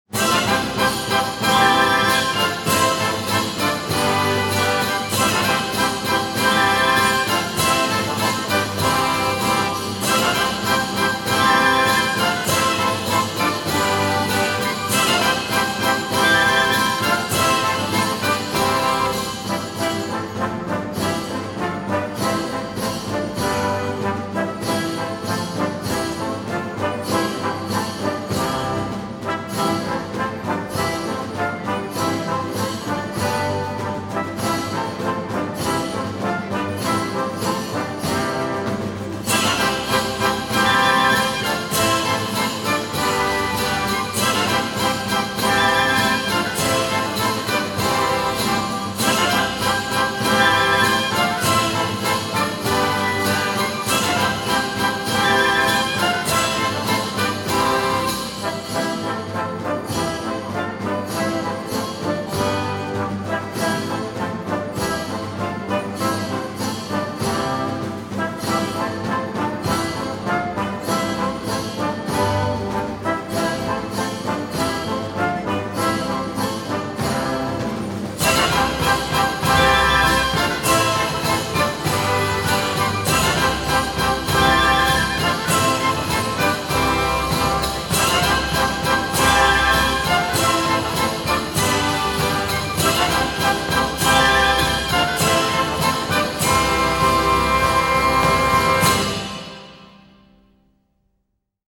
Orquesta
Danza